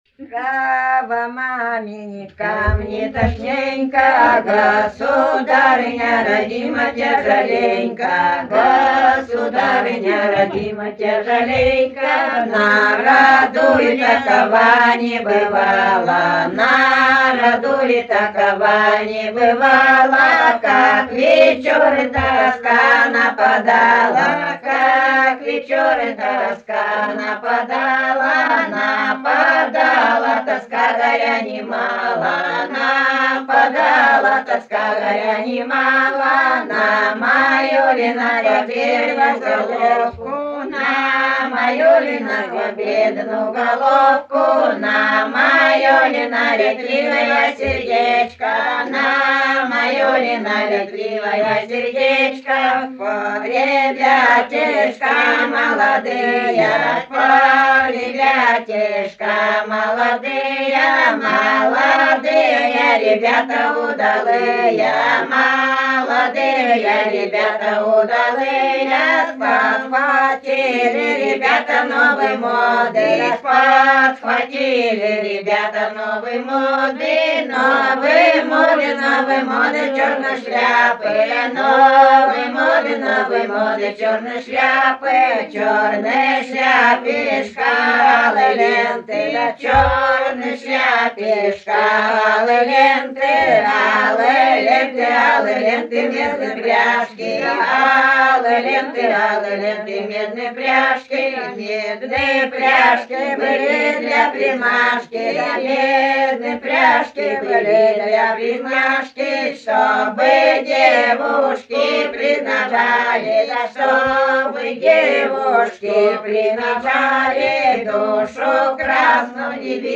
Исполнитель: фольклорная группа с. Шуньга
Место записи: с. Шуньга, Медвежьегорский район, Республика Карелия.